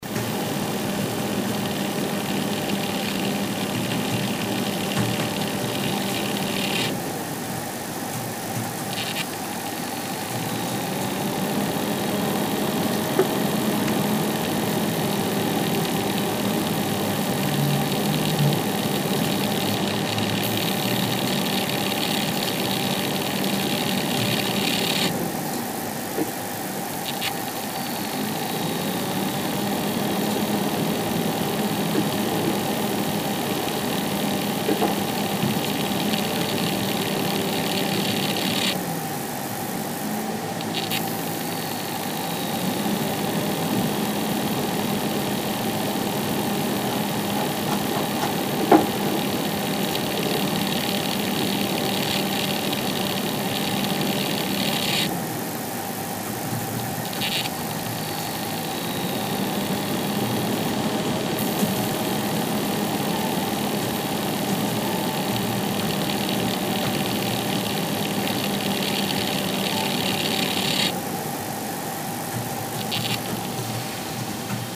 На этой странице собраны различные звуки работающего вентилятора: от тихого гула компьютерного кулера до мощного потока воздуха из бытового прибора.
Шум от работы двух вентиляторов одновременно